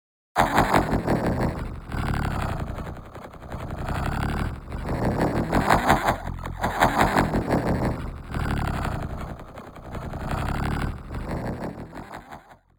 Evil Laught 02 HR
awesome dark evil gladiator haha hcm hell laught sound effect free sound royalty free Funny